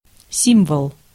Ääntäminen
IPA: [sim.boːl]